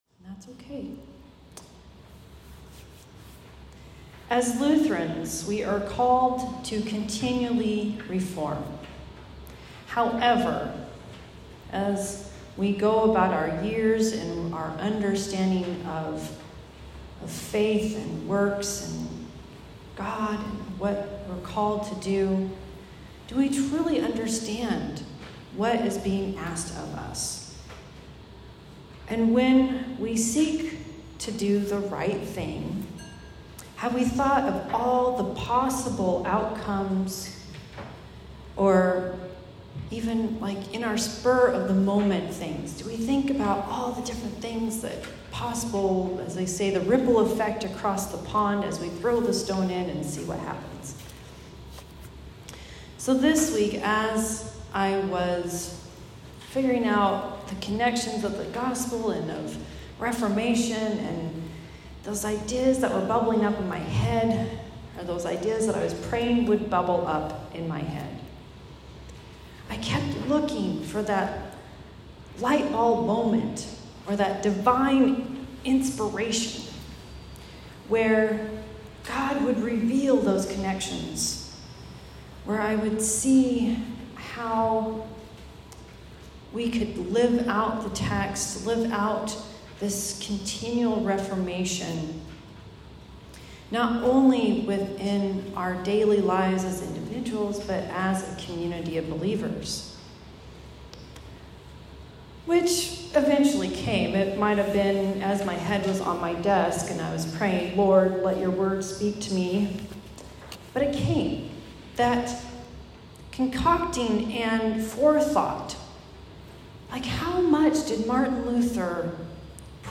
Reformation Sunday Sermon